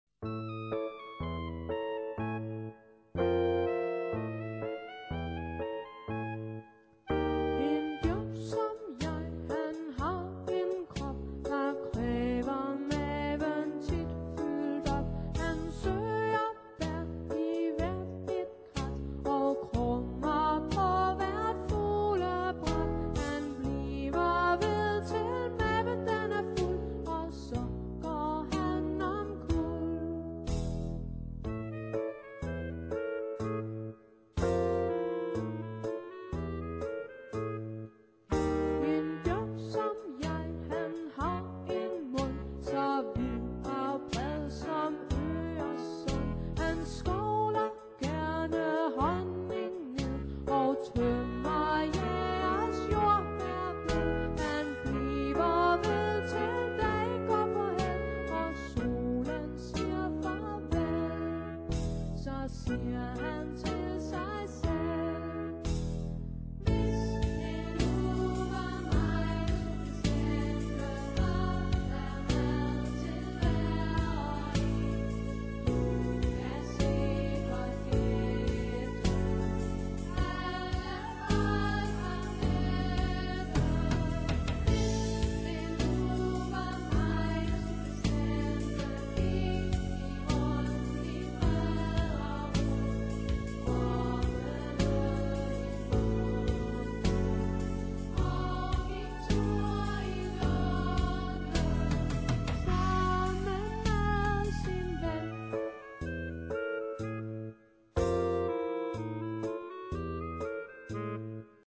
Derudover hører vi en stribe smukke og fængende ørehængere.